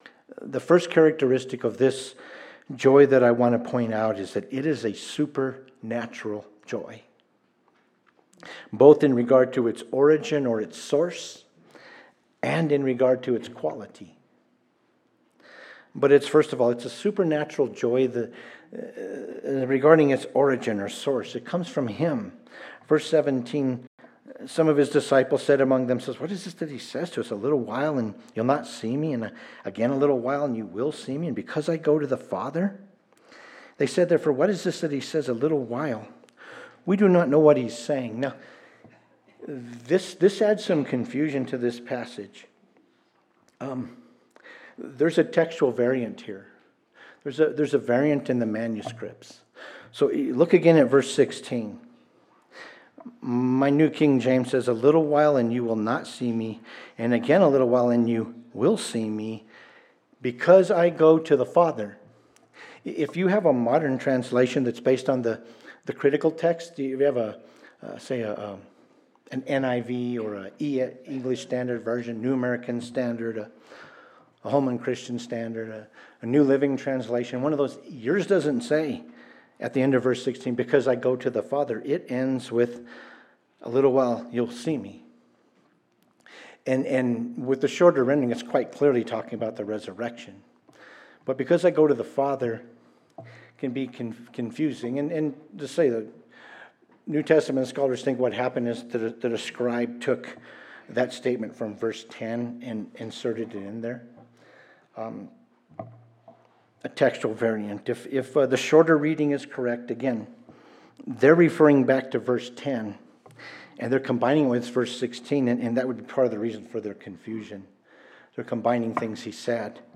Communion Services